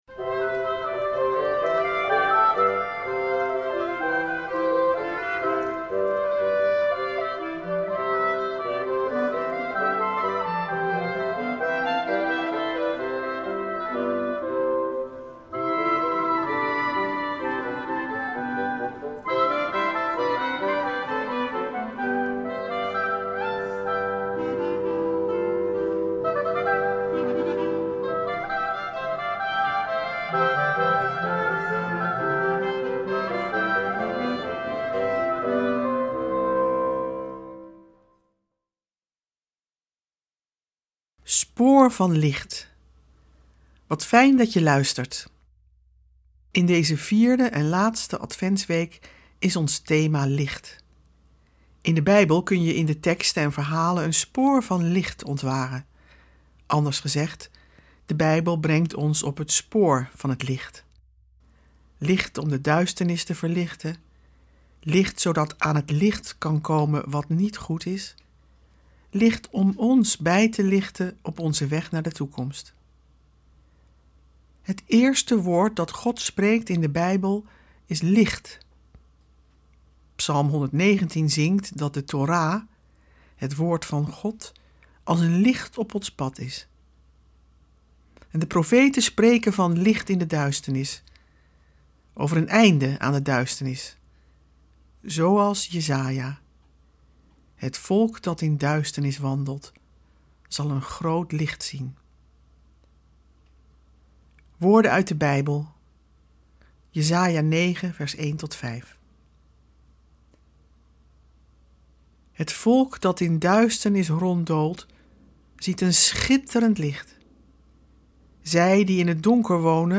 Met muziek en een actuele reflectie.